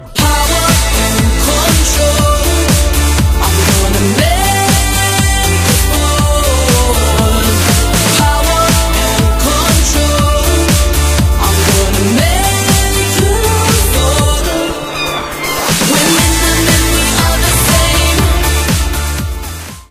This is a sound sample from a commercial recording.
Reduced quality: Yes (65 kbps)